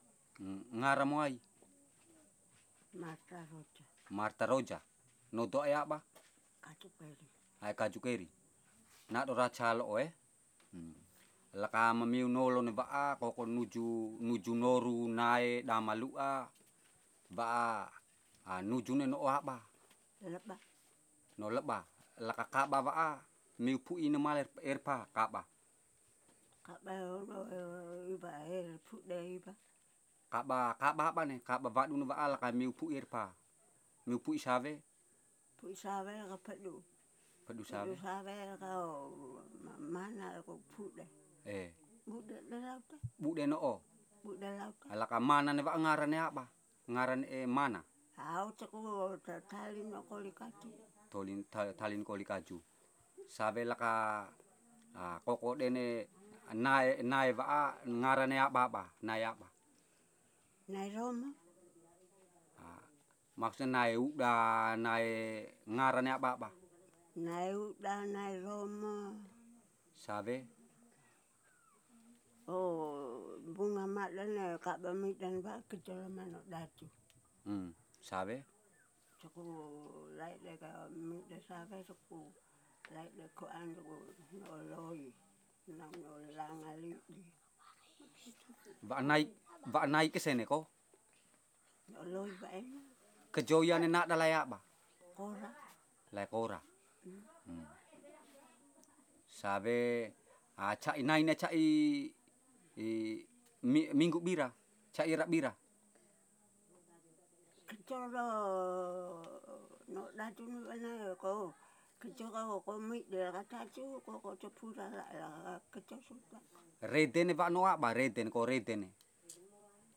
Recording made in kampong Kaju keri, Keli domain.
dc.type.dcmiSound